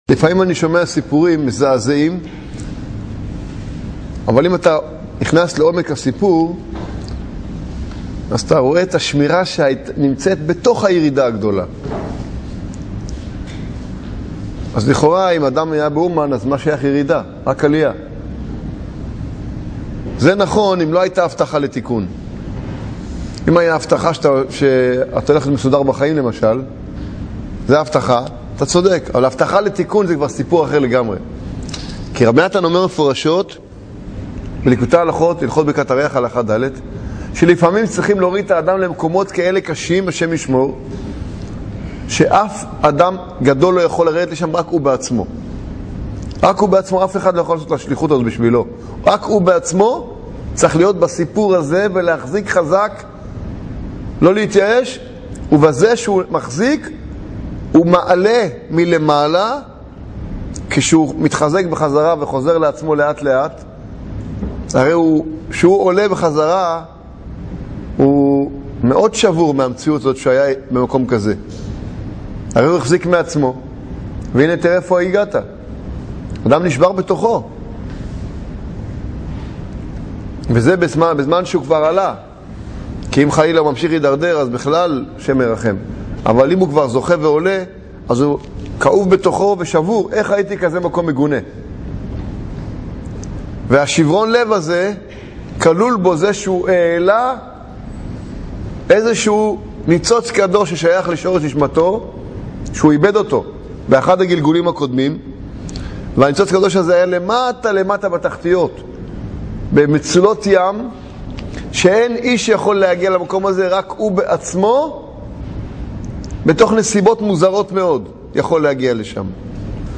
שיעור על תפילה והתבודדות חלק שני